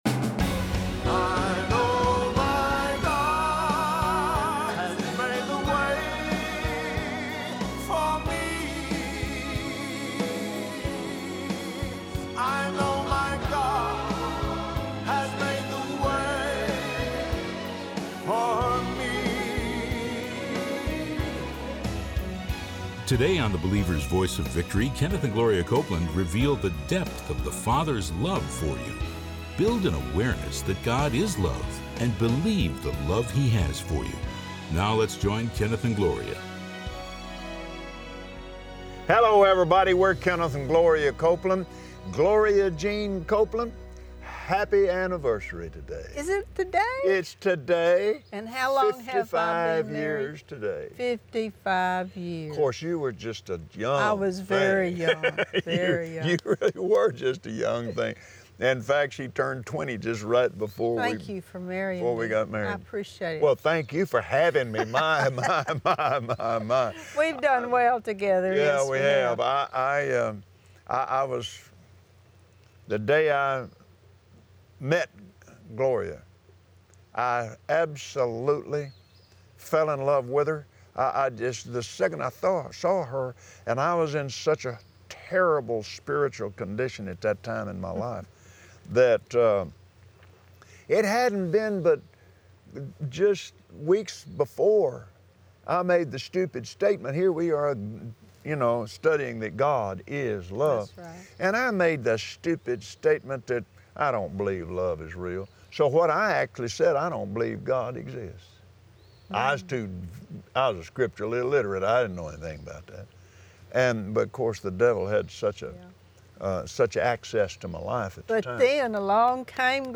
Watch Kenneth and Gloria Copeland on Believer’s Voice of Victory explain what the God kind of love really looks like and how it unlocks the door to THE BLESSING in your life!